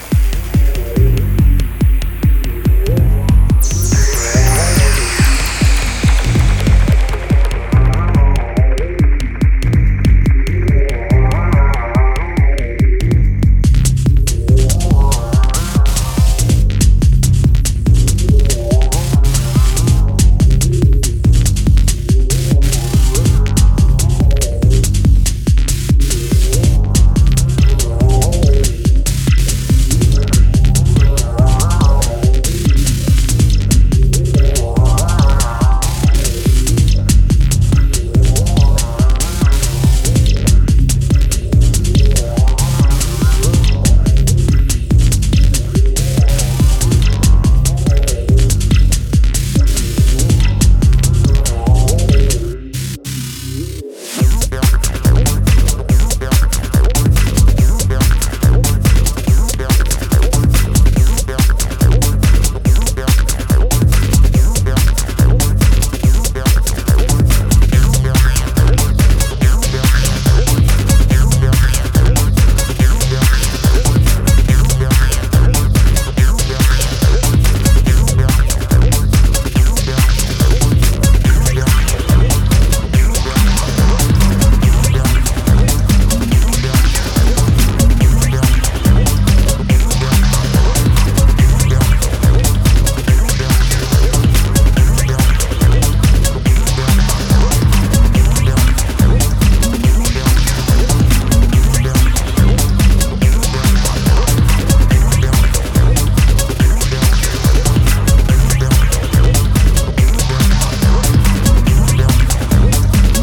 for another trip into tech house